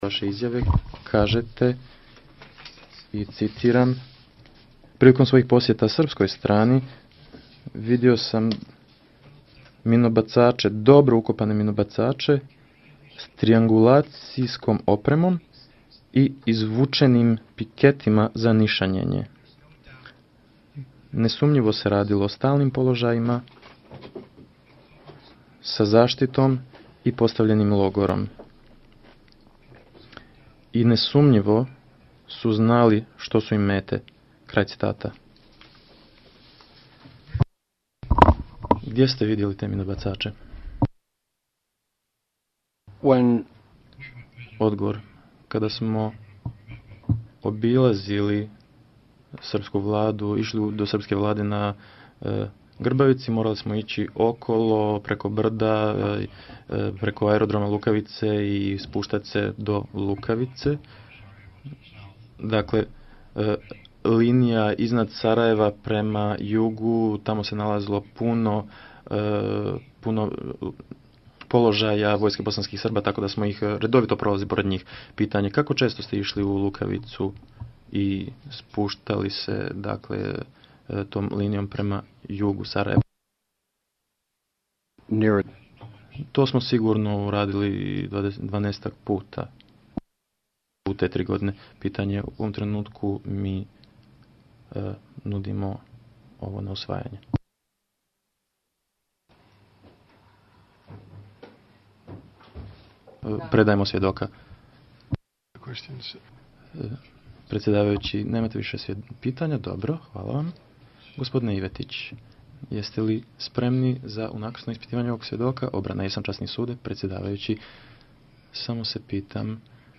svjedoči na suđenju Ratku Mladiću u Hagu